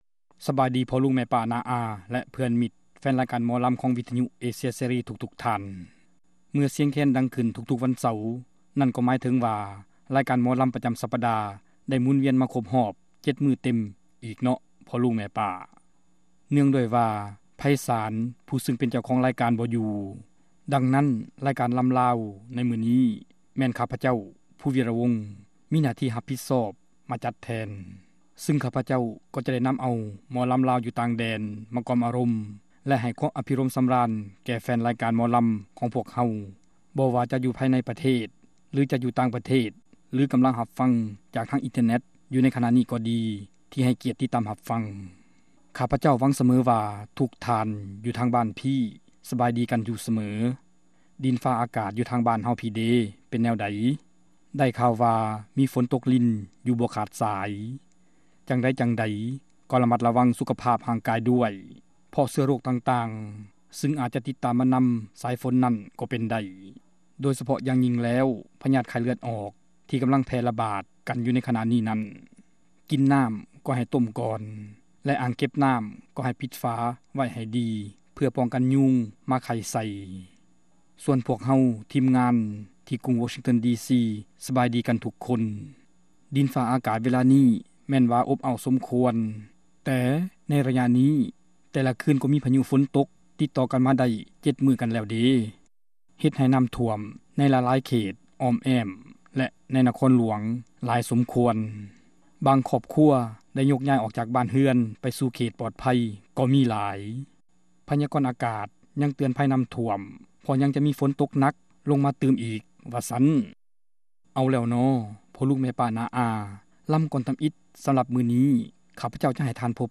ຣາຍການໜໍລຳ ປະຈຳສັປະດາ ວັນທີ 30 ເດືອນ ມິຖຸນາ ປີ 2006